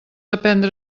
speech-commands